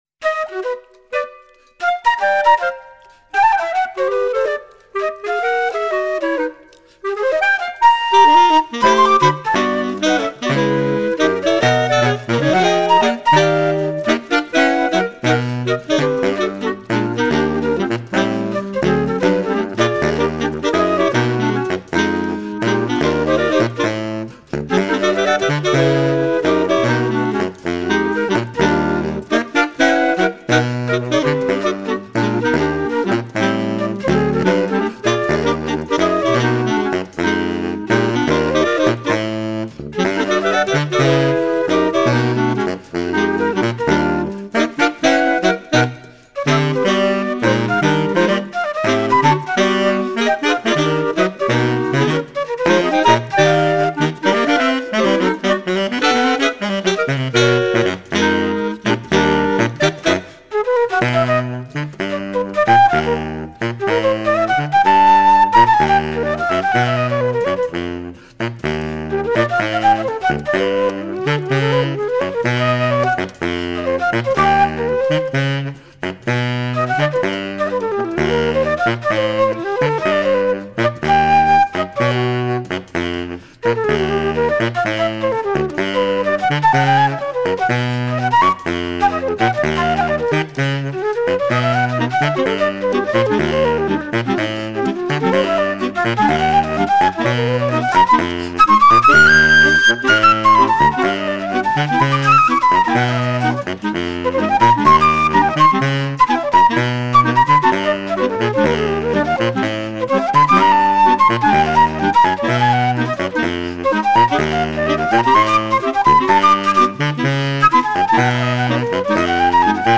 Bossa Nova